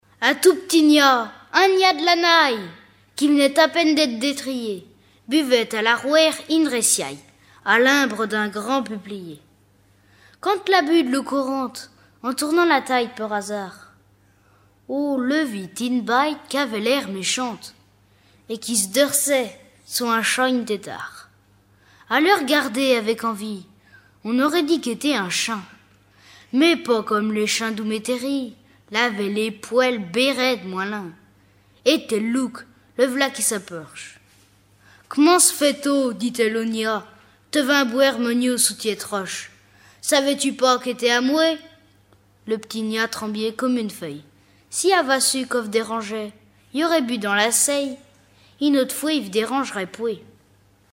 Genre fable
Catégorie Récit